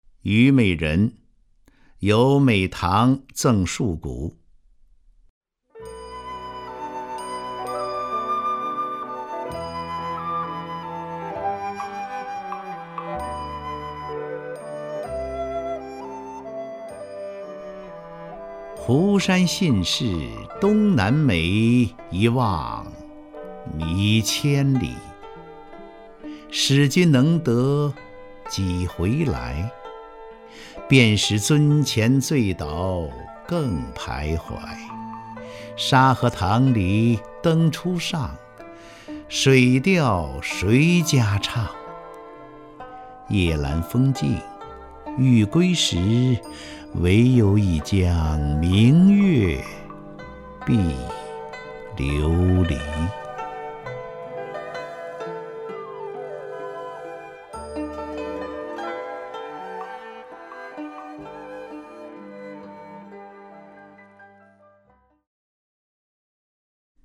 张家声朗诵：《虞美人·湖山信是东南美》(（北宋）苏轼)
名家朗诵欣赏 张家声 目录
YuMeiRenHuShanXinShiDongNanMei_SuShi(ZhangJiaSheng).mp3